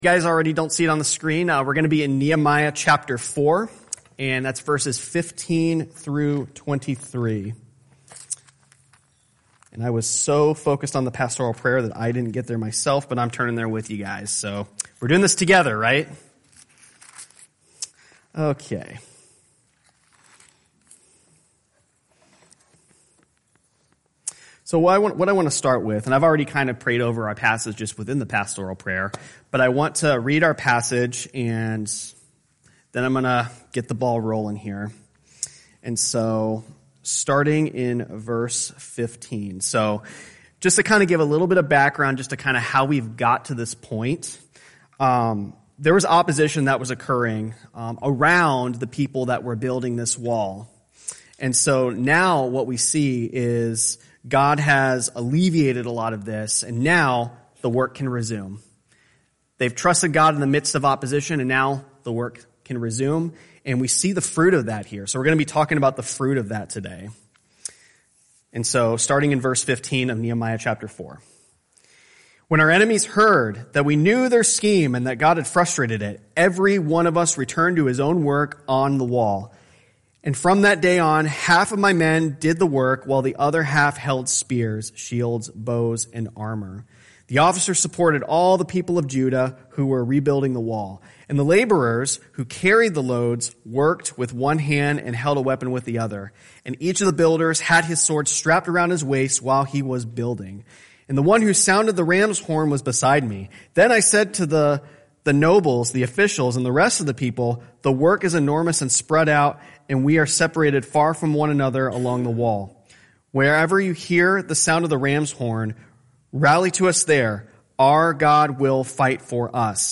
Sunday Worship
Tagged with Sunday Sermons